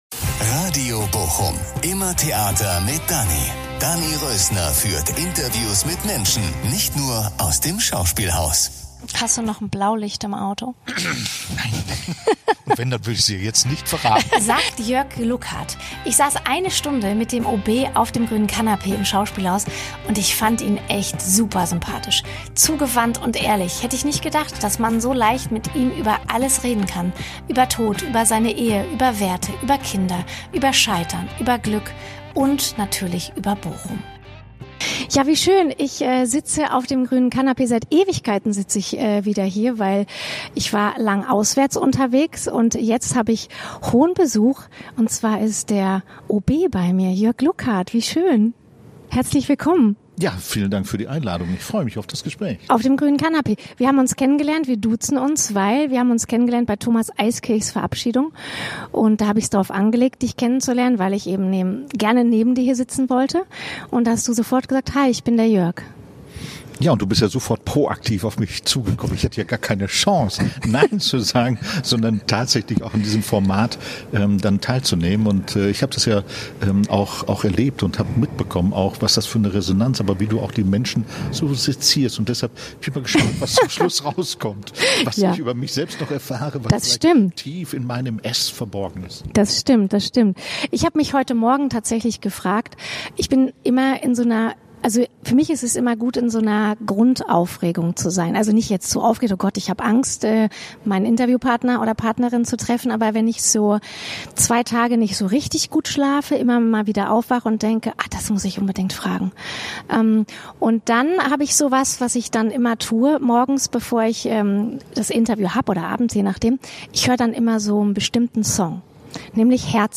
wieder Persönlichkeiten aus Bochum auf dem grünen Canapée im Schauspielhaus. Erster Gast im neuen Jahr ist der neue Oberbürgermeister Jörg Lukat.